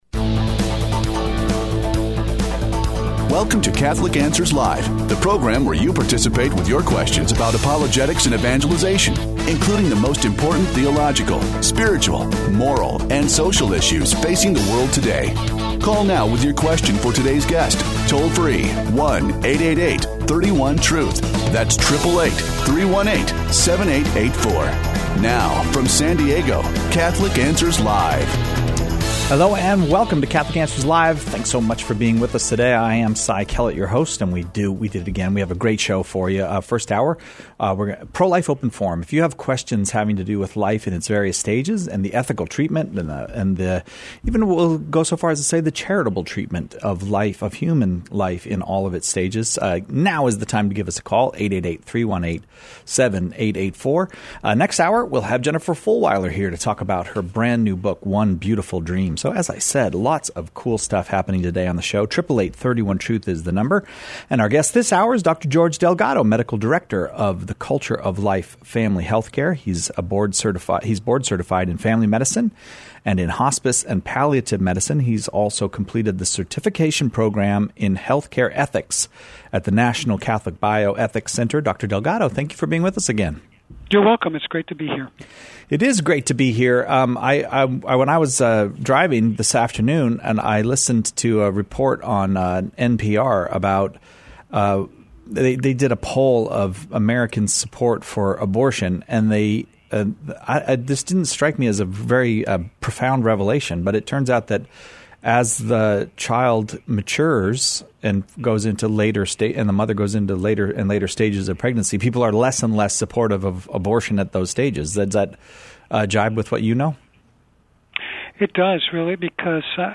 takes listener calls about the beginning and end of life and about the proper use of medical technology.